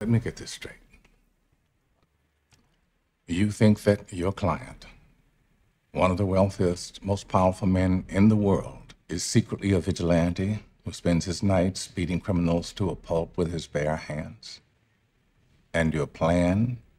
narator_out.wav